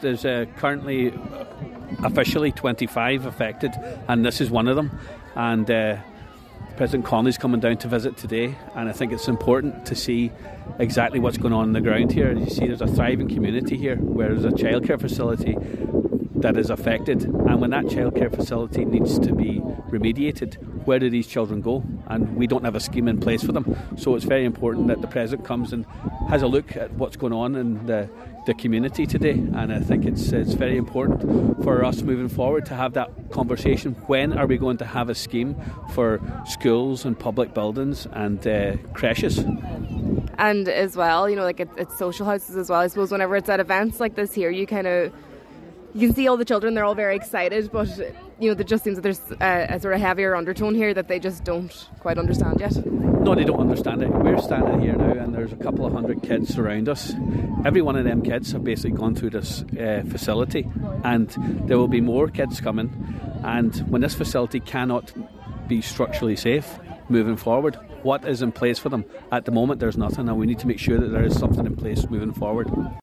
Deputy Charles Ward echoes the call from Senator Flynn and says it’s important the President sees what’s going on in communities affected by defective concrete: